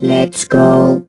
rick_start_vo_03.ogg